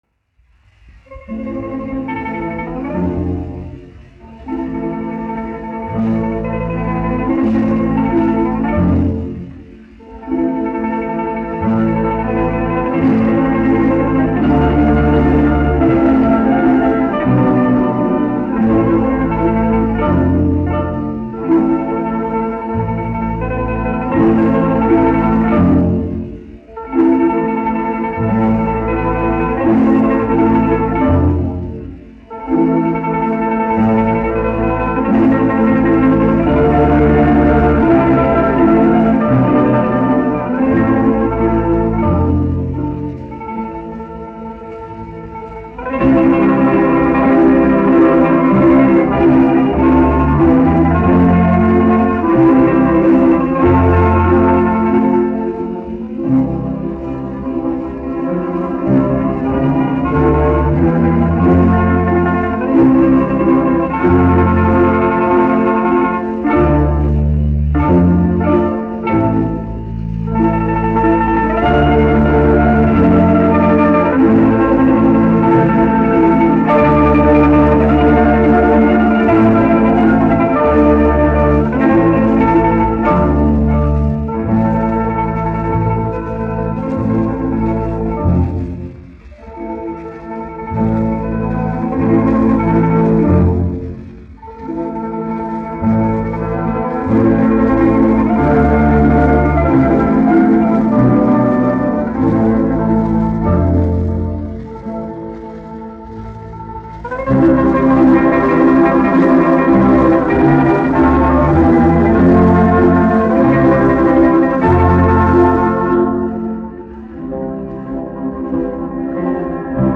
1 skpl. : analogs, 78 apgr/min, mono ; 25 cm
Krievu tautasdziesmas
Latvijas vēsturiskie šellaka skaņuplašu ieraksti (Kolekcija)